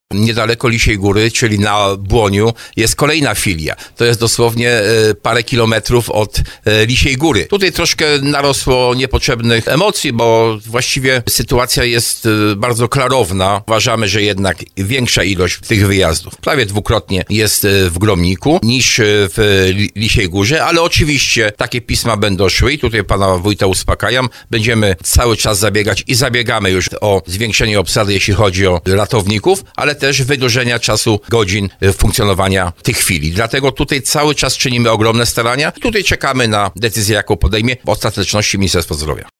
Mówił o tym w audycji Słowo za Słowo w związku ze zmianami jakie mają nastąpić od 1 stycznia.
Do tej sytuacji odniósł się starosta tarnowski Jacek Hudyma.